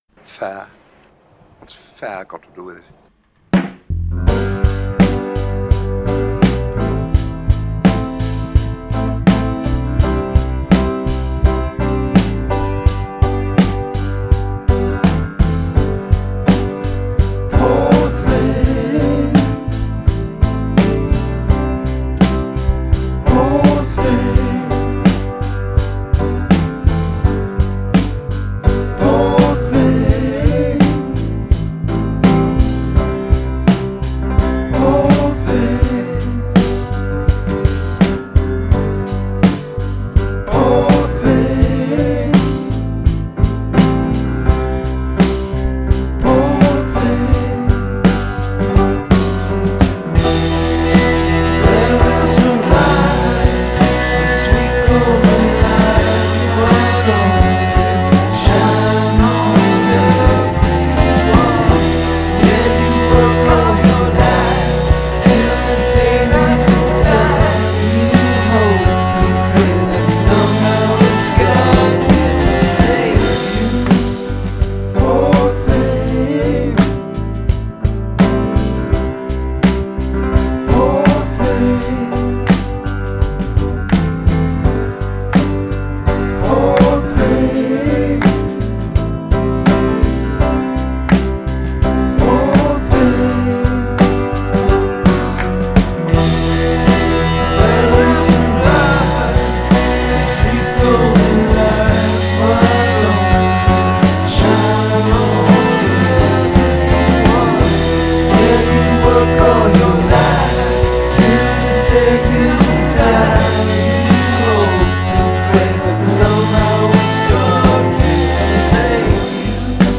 rock alternatif
recèle des trésors de mélodies sombres et hypnotiques.